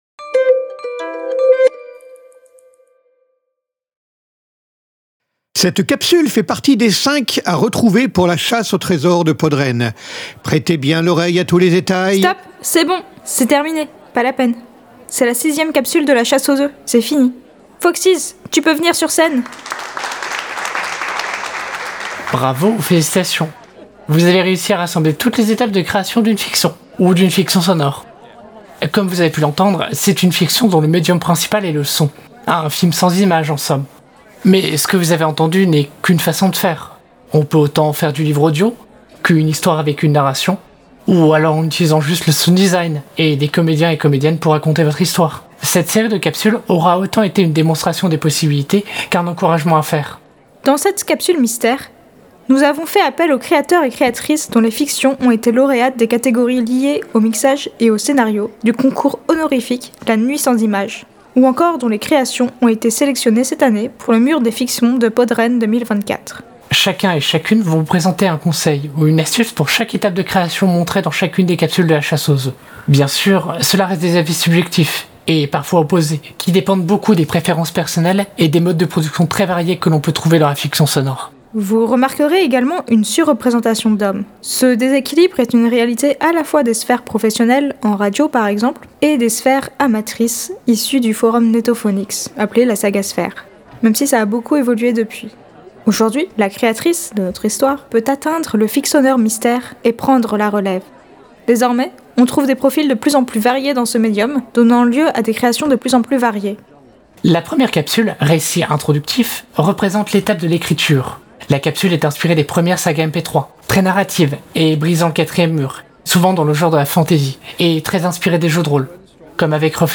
Le festival est la première délocalisation en IDF de PodRennes, organisé par l’association de podcast Badgeek.